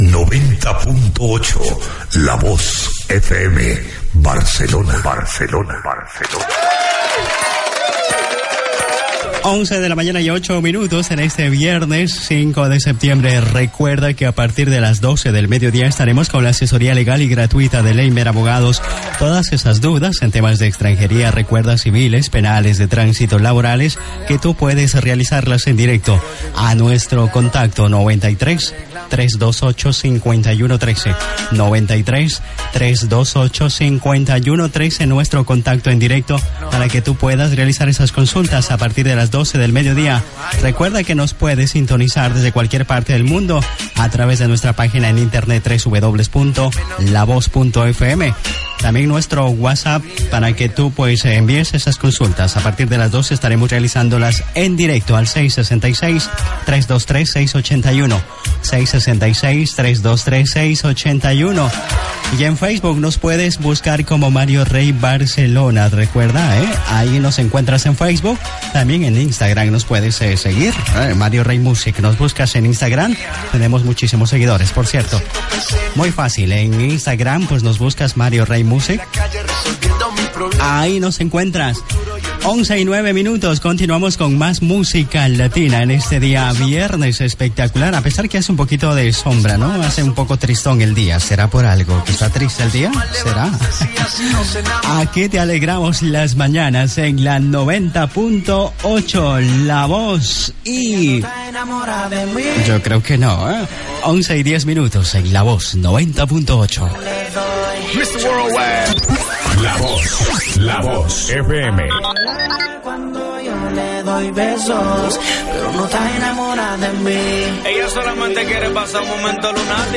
Indicatiu, data, telèfon de participació de l'espai de Mario Rey Abogados, hora, identificació, hora, indicatiu i tema musical
FM